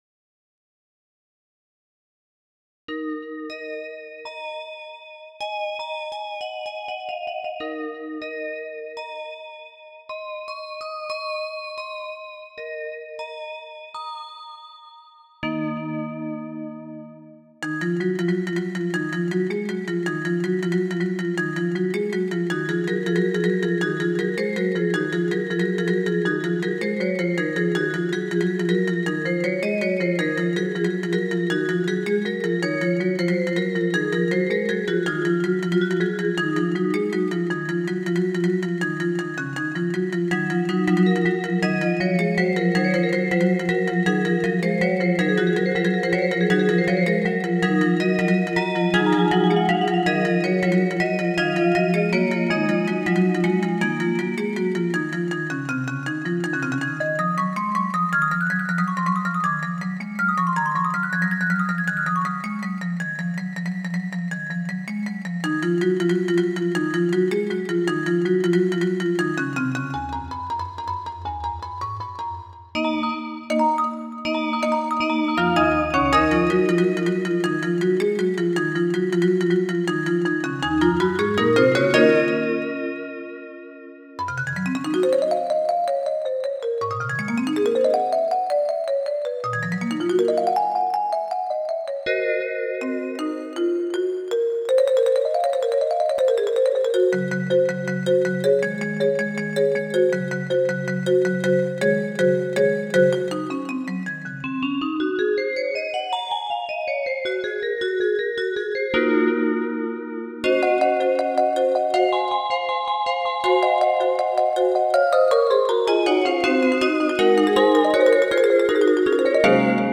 音源は全てVSC-88です。
マリンバとヴィブラフォンの二重奏曲。